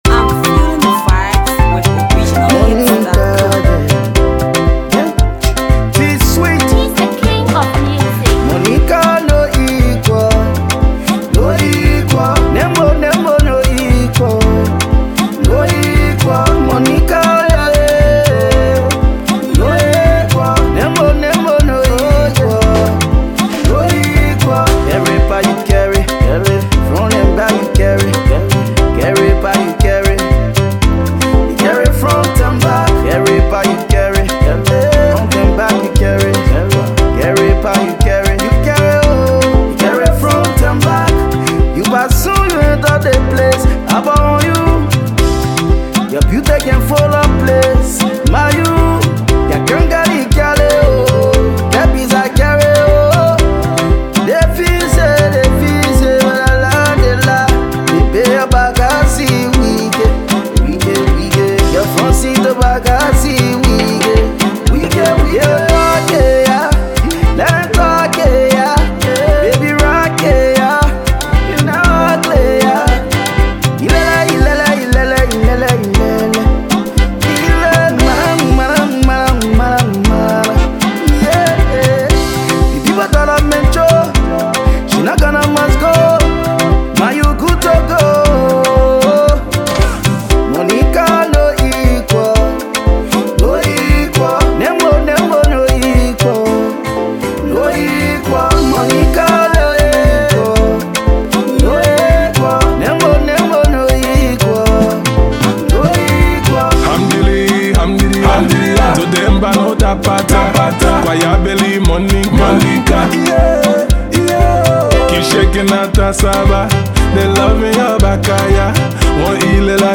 a nice banger